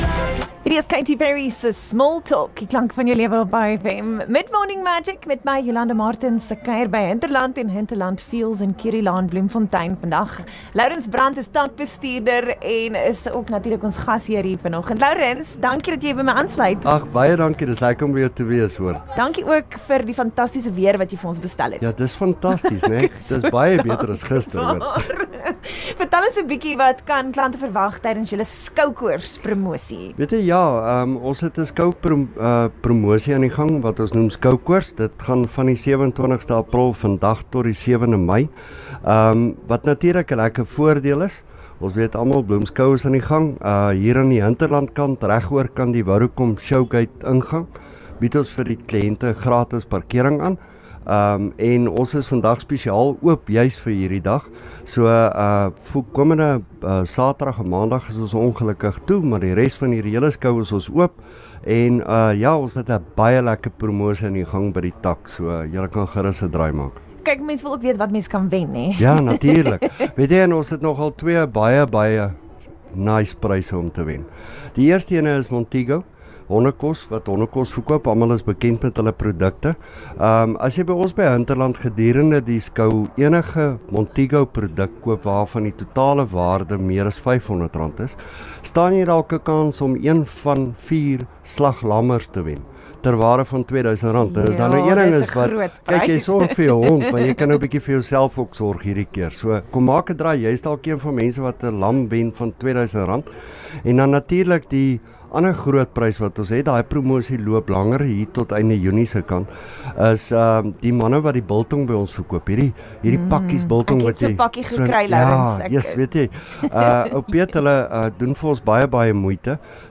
28 Apr Hinterland se SkouKoors promosies - onderhoud 1
Mid-Morning Magic was regstreeks vanaf Hinterland en Hinterland Fuels in Curielaan, Bloemfontein, op 27 April 2022.